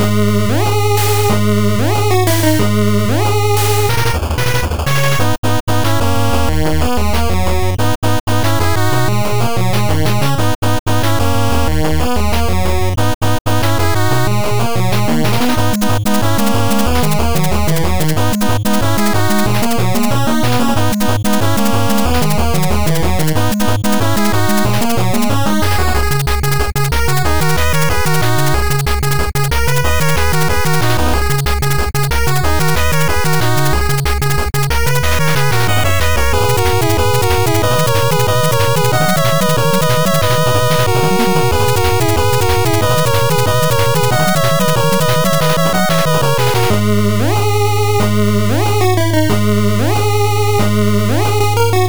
8bit music for final boss battle.